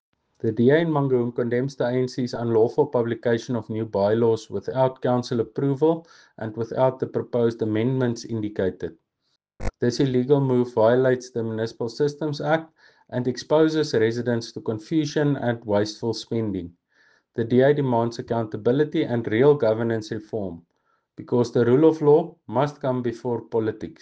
English and Afrikaans soundbites by Cllr Tjaart van der Walt and